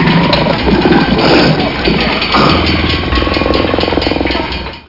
Amiga 8-bit Sampled Voice
baa.mp3